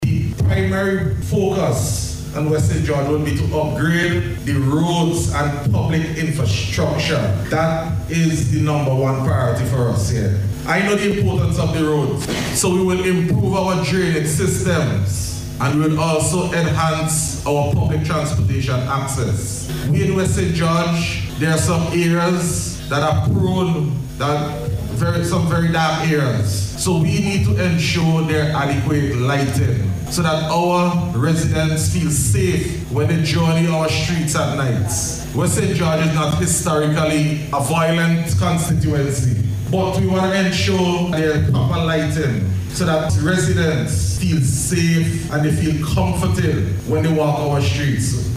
Area representative and Minister of Youth, Sports, Culture and Creative Industries, Honourable Kaschaka Cupid, provided some details of these projects during a Community Development Town Hall Meeting in Gomea on Saturday February 28.